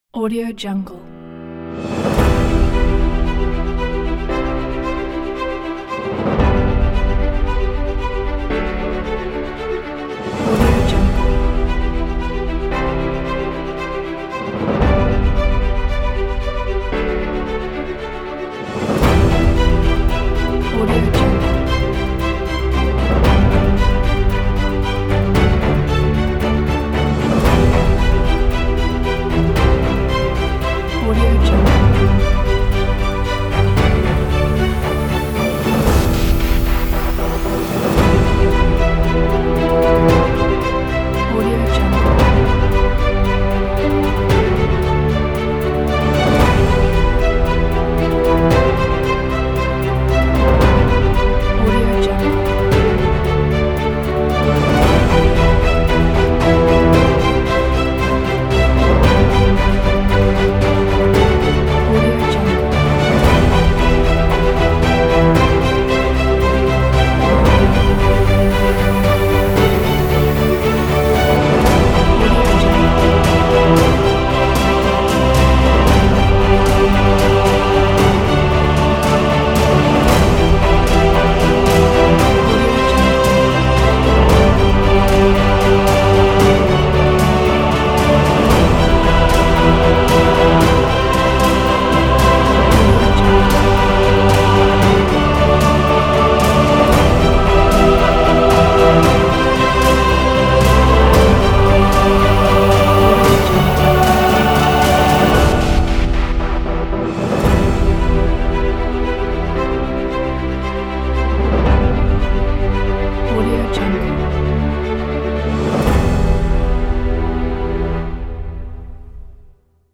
موزیک زمینه موزیک زمینه انگیزشی
• سمپل ریت: Stereo 16-Bit , 44.1 kHz